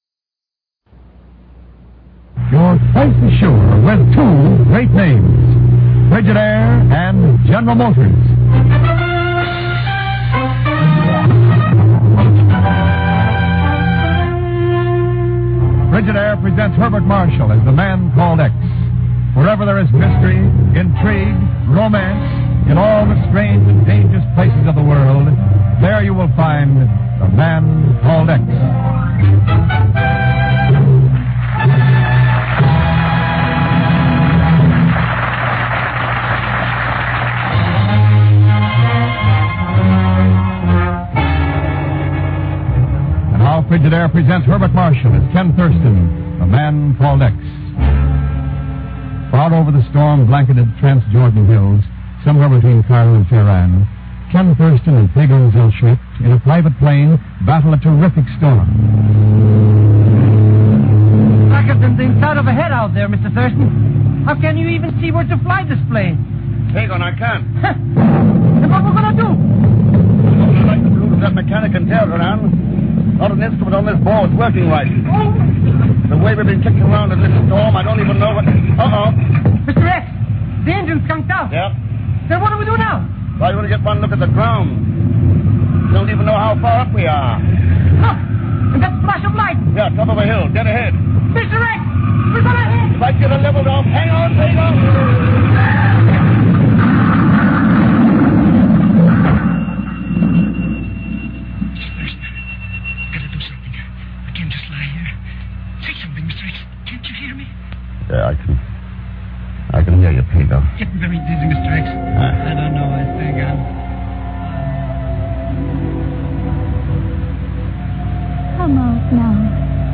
OTR Christmas Shows - From A Starlit Hill - rough in spots - 1947-12-21 NBC The Man Called X